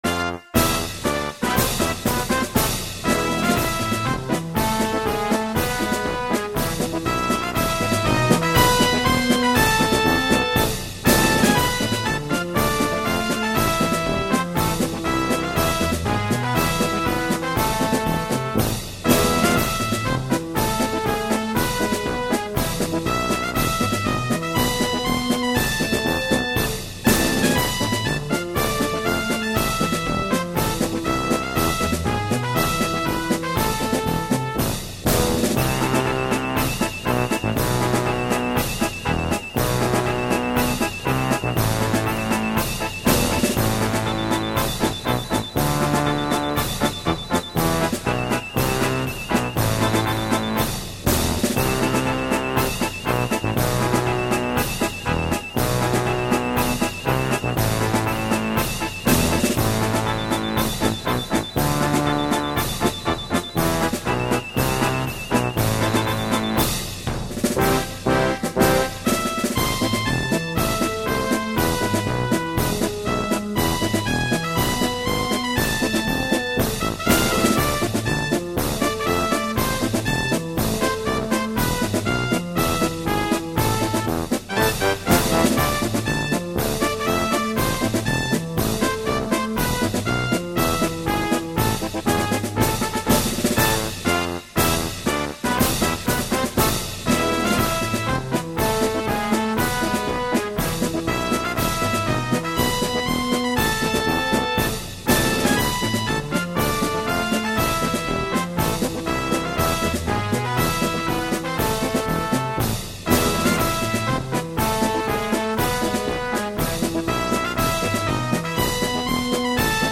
Марш "Прощание славянки".